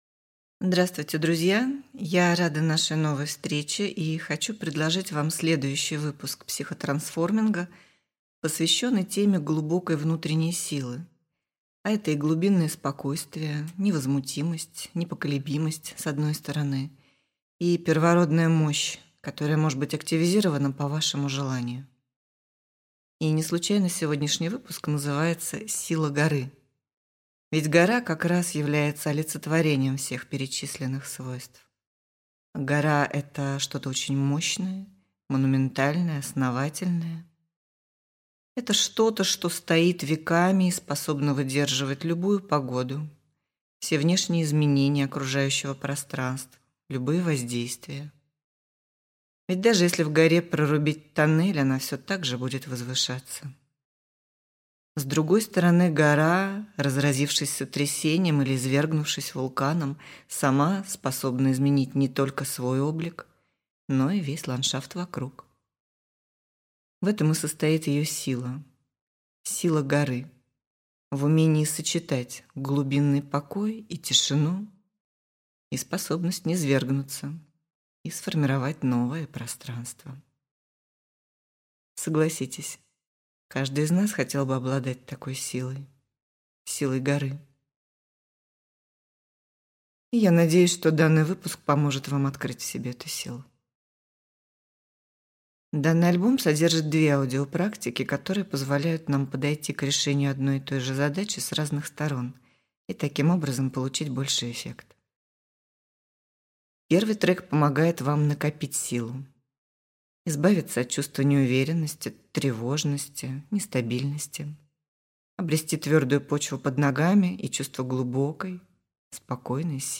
Аудиокнига Сила горы. Йога нидра. Психотрансформинг: практики для расслабления и психокоррекции | Библиотека аудиокниг